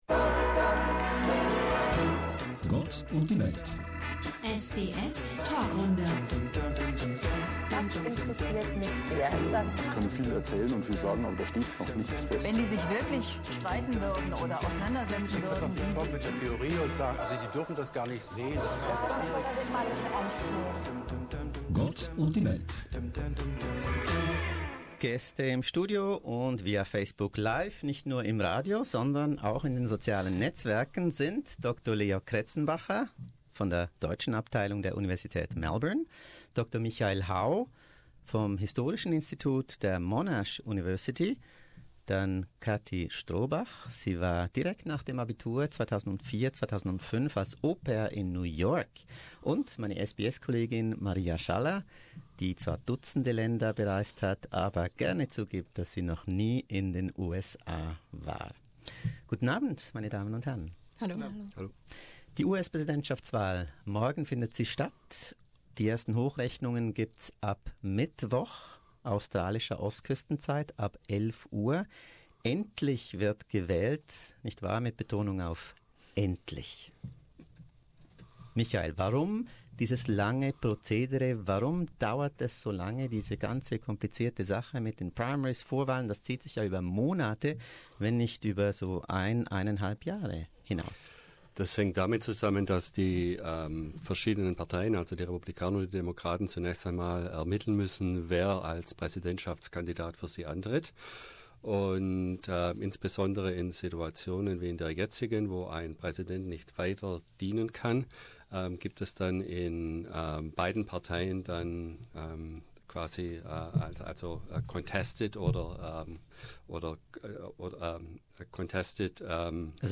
Clinton, Trump and the agony of making the right decision. On the eve of the US presidential election, an SBS panel discussion was held at SBS Radio and Facebook Live.